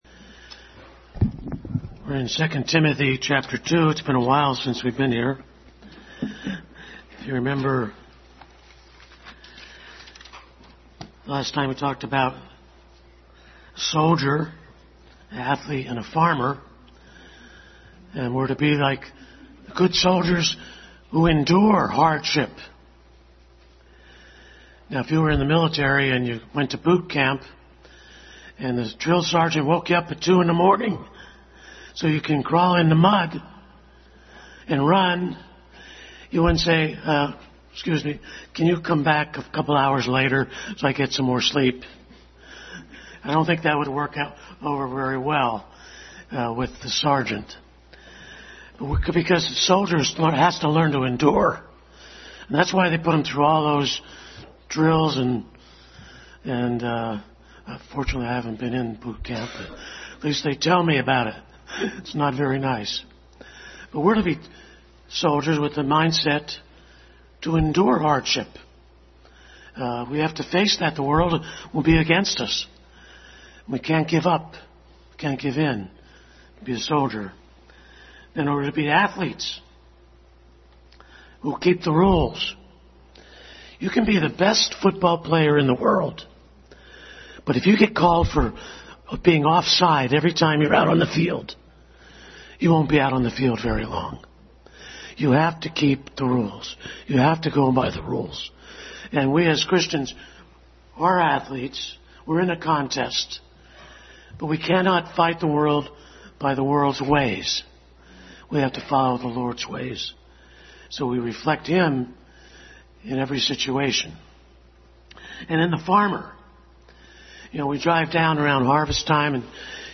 2 Timothy 2:14-26 Passage: 2 Timothy 2:14-26, 1 Timothy 1:4-5 Service Type: Sunday School